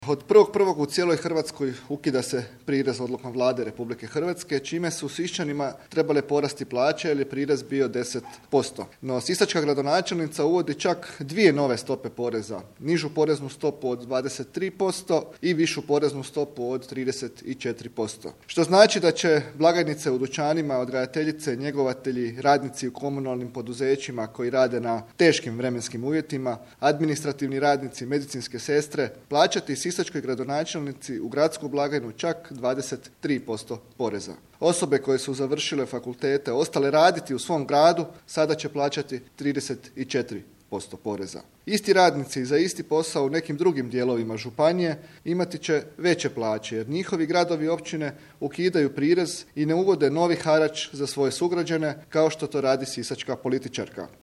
Gradska organizacija HDZ-a Sisak danas je održala konferenciju za medije vezanu uz odluku sisačke gradonačelnice Kristine Ikić Baniček da se od 1. siječnja 2024. godine Siščanima uvode nove porezne stope.